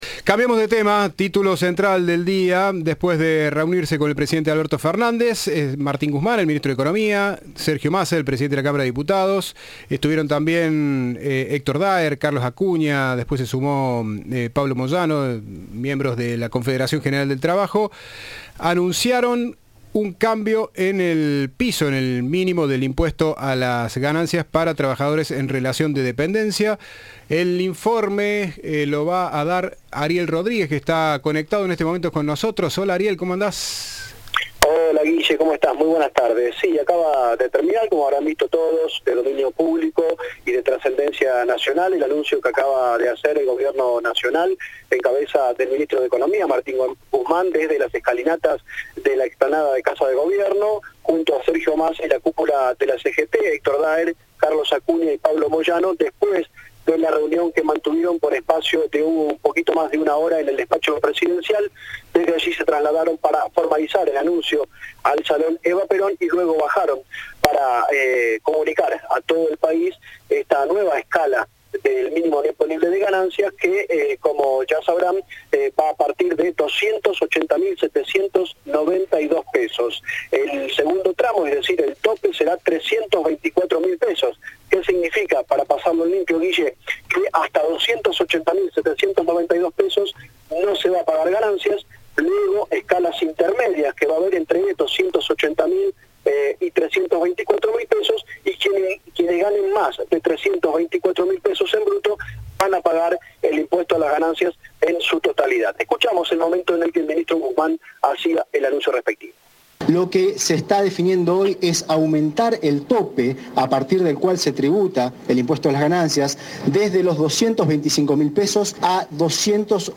Lo anunció el ministro de Economía, Martín Guzmán, en la explanada de la Casa de Gobierno, acompañado por el cosecretario de la CGT, Héctor Daer, y el titular de la cámara de diputados, Sergio Massa.